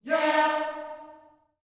YEAH D#4B.wav